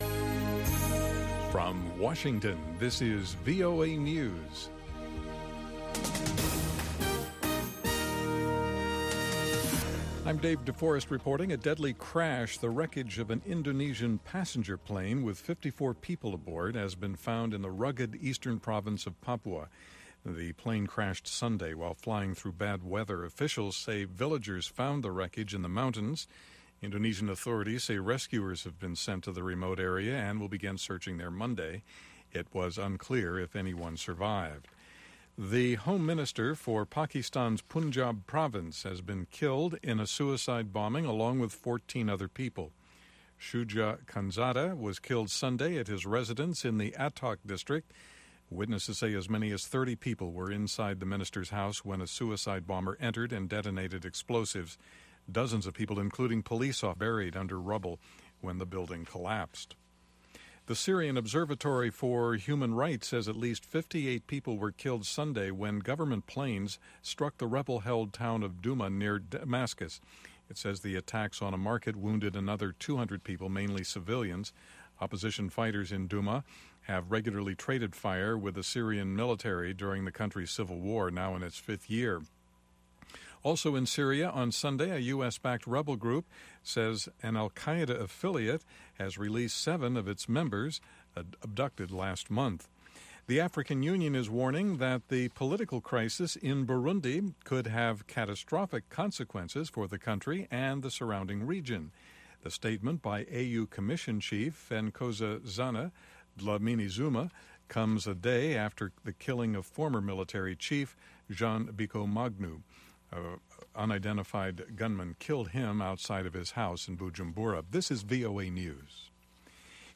from N’dombolo to Benga to African Hip Hop
the best mix of pan-African music